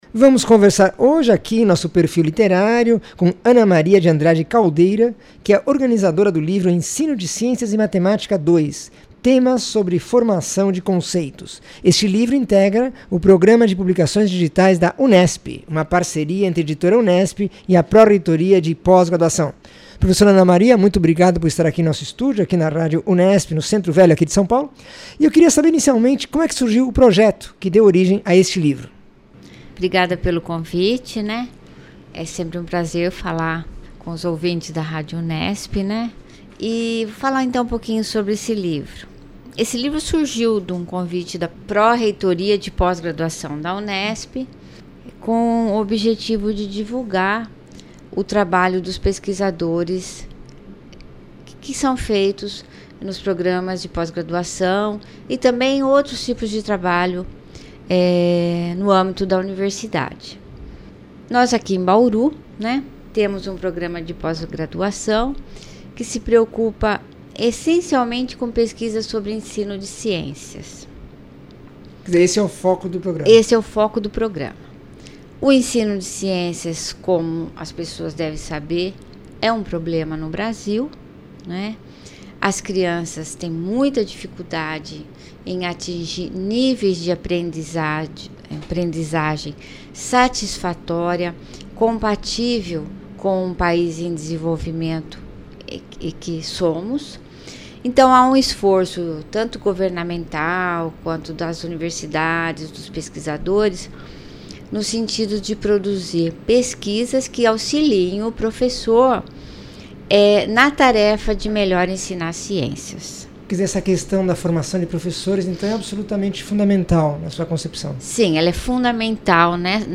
entrevista 660
Entrevista